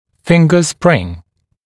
[‘fɪŋgə sprɪŋ][‘фингэ сприн]пальцевидная пружина